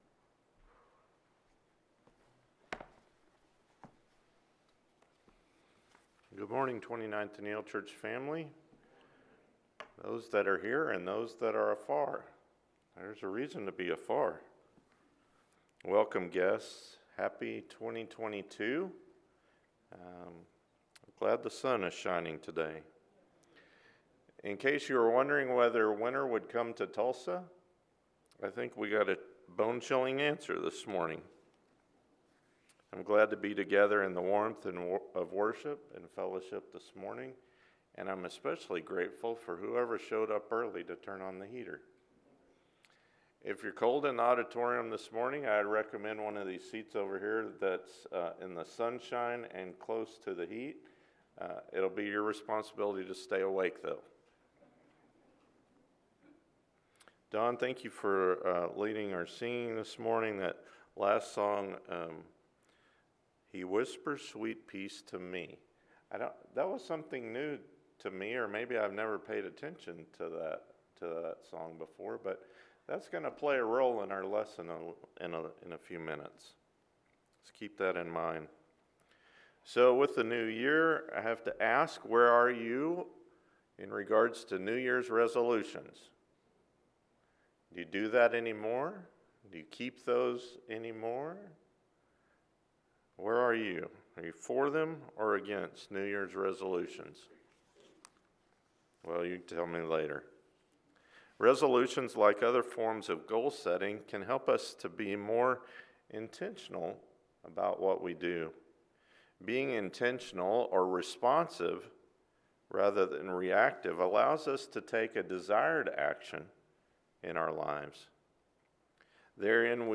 Prayerful – Luke 5:16 – Sermon